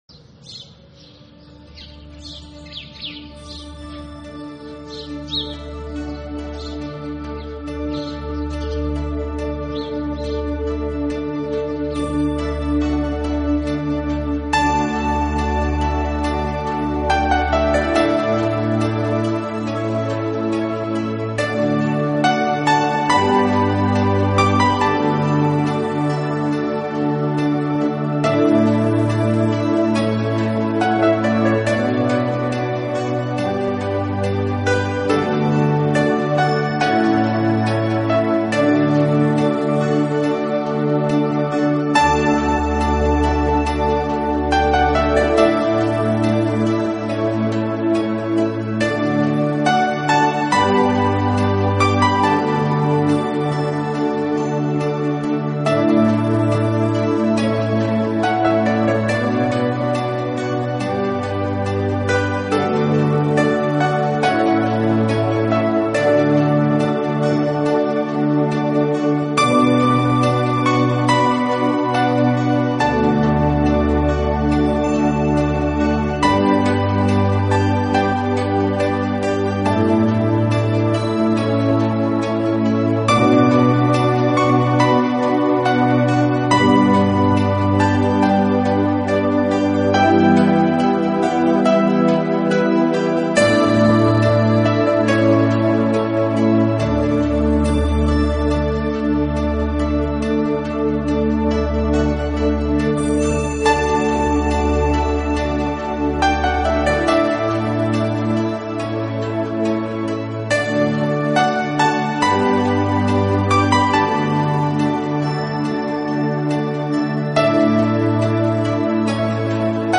以如梦似幻的清新自然音乐，著称乐坛。
那轻灵脱俗的旋律将引导着您远离尘世的喧嚣，亲近浩瀚的大自然。